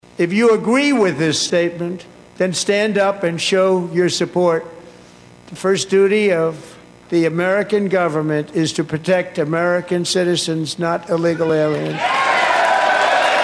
(Washington, DC)  —  Minnesota Democrat Congresswoman Illhan Omar got into a shouting match with President Trump during his State of the Union address.